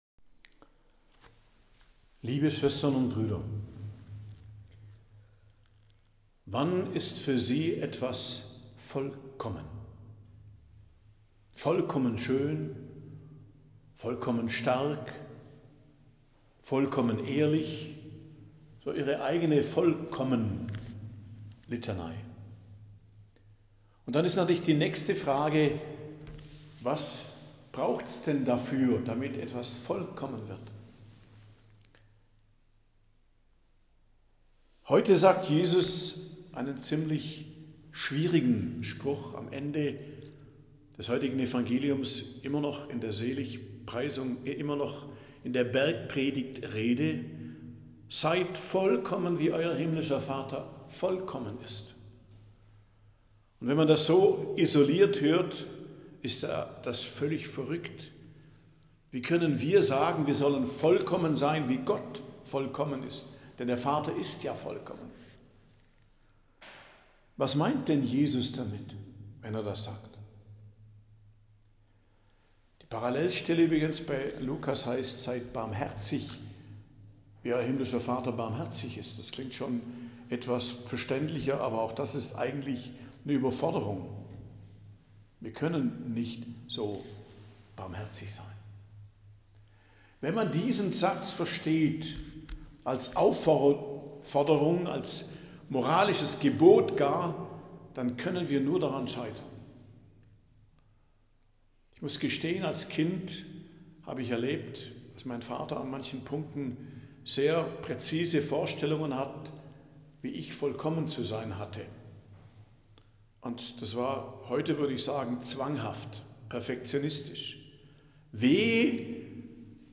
Predigt am Dienstag der 11. Woche i.J., 17.06.2025 ~ Geistliches Zentrum Kloster Heiligkreuztal Podcast